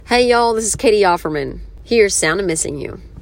LINER